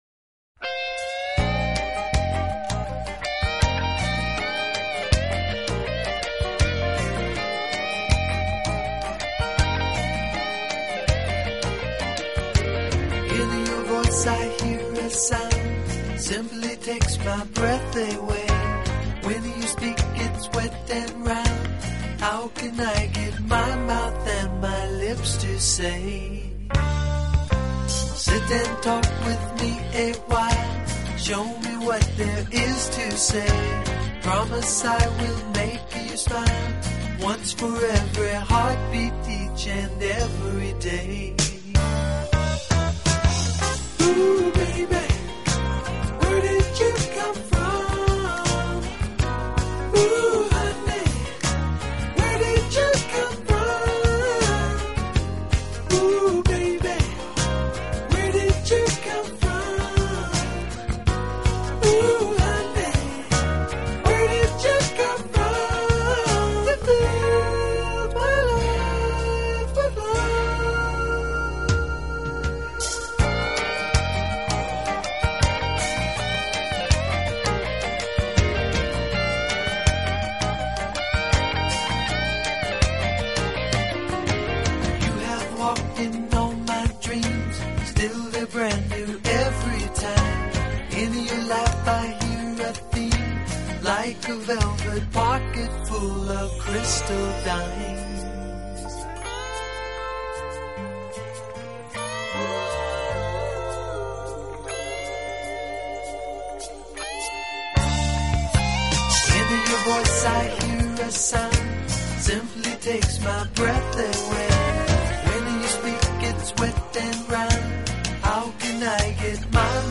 【爵士吉他】